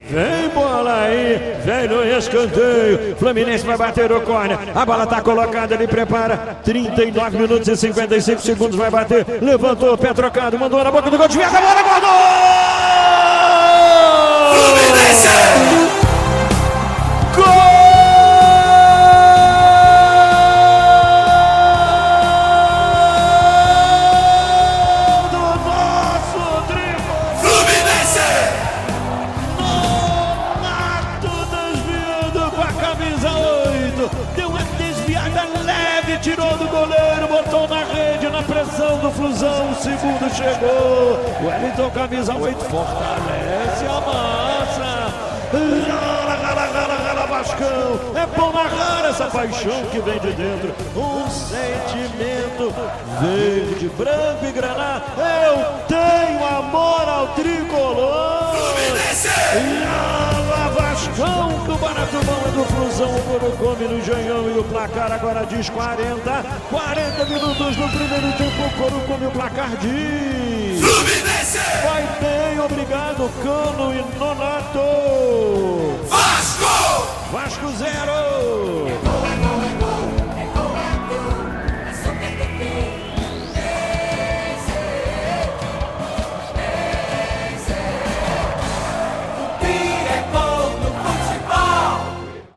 Ouça os gols da vitória do Fluminense sobre o Vasco pelo Carioca com a narração de Luiz Penido